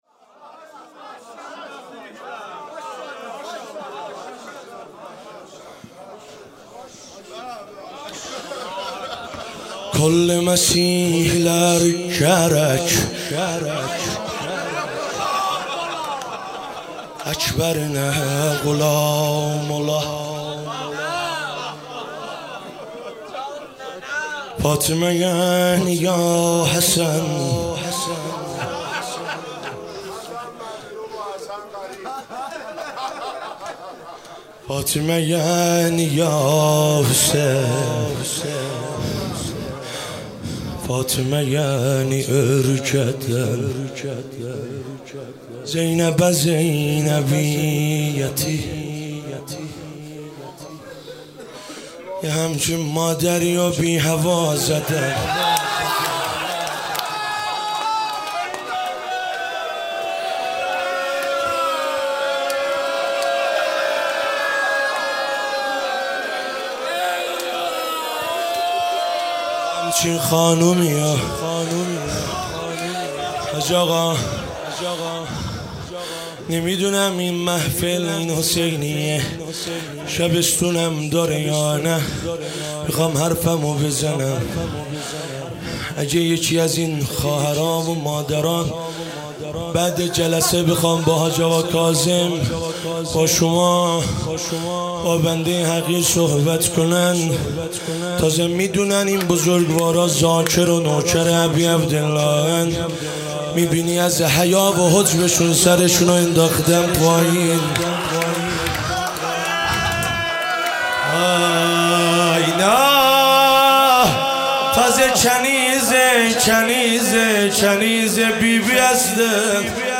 ایام فاطمیه دوم به روایت ۹۵ روزه روضه هیئت رایه الرضا علیه السلام سخنران